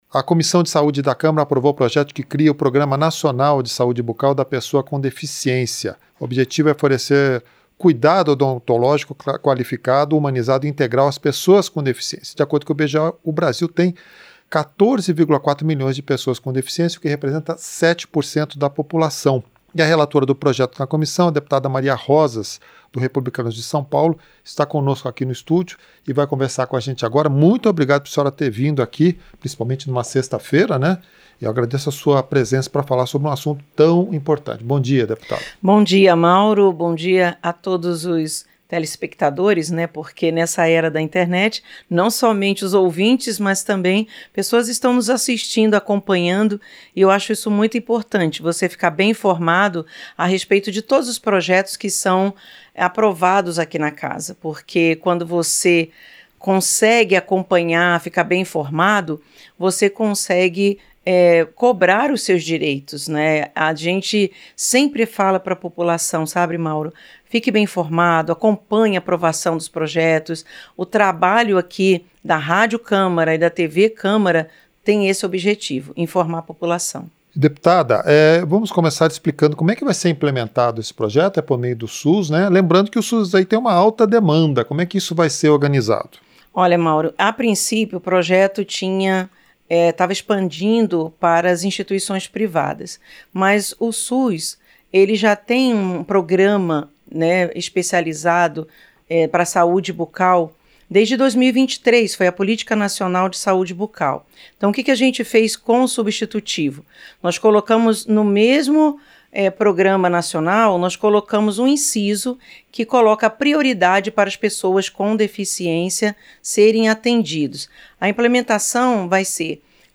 Entrevista - Dep. Maria Rosas (Rep-SP)